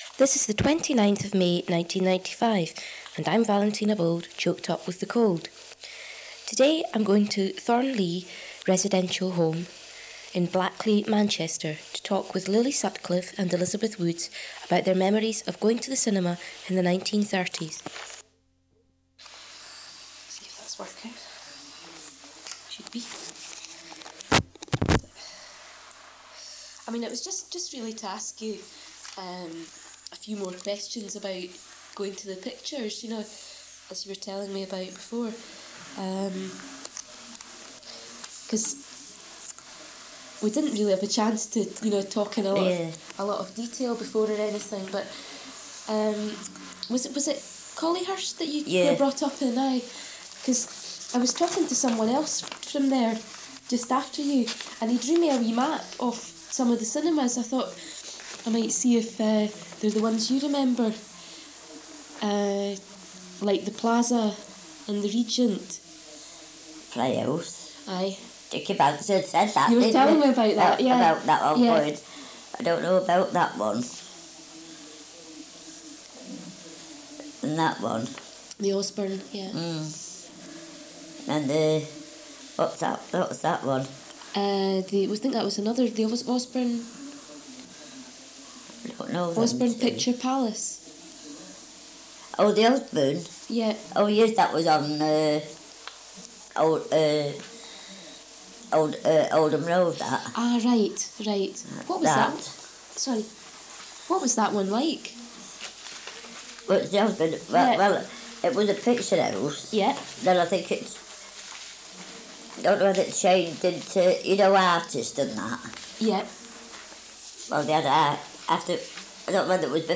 Sound Quality: Fair.